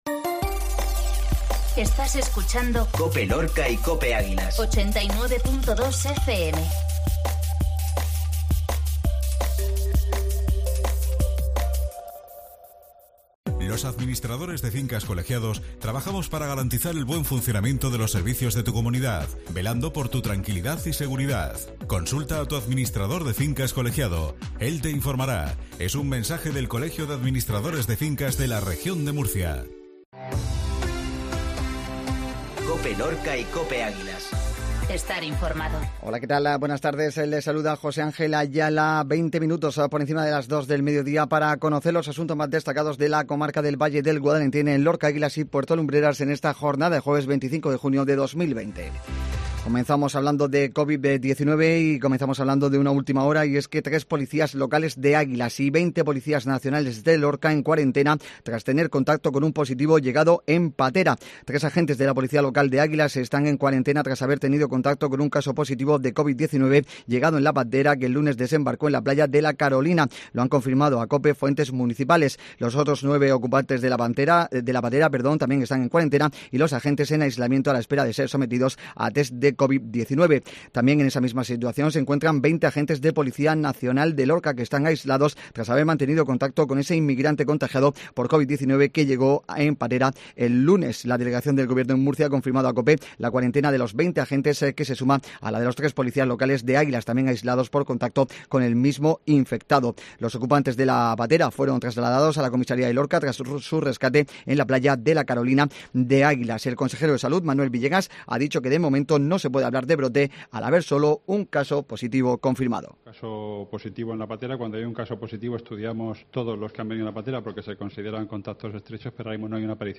INFORMATIVOS MEDIODÍA JUEVES